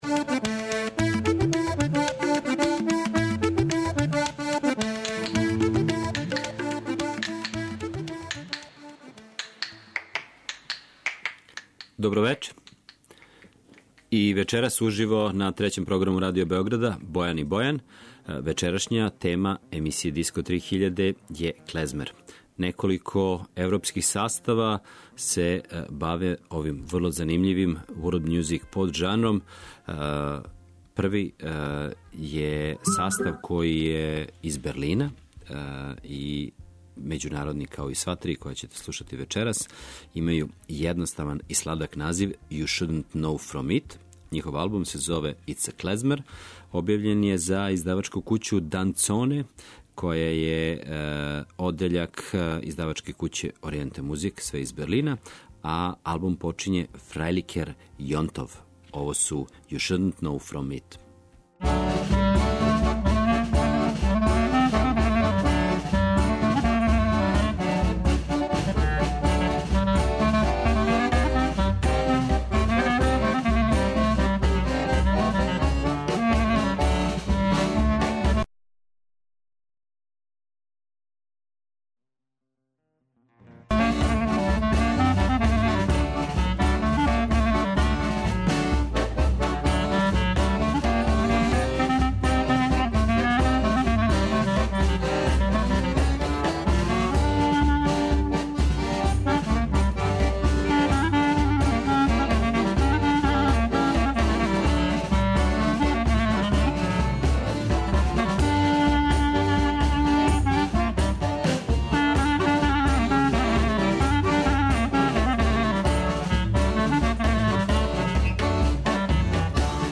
Клезмер музике